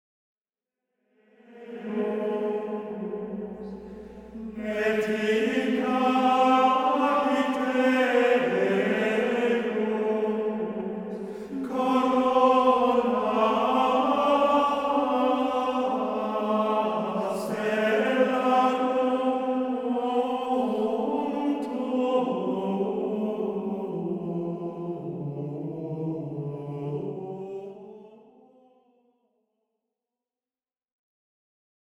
Leçon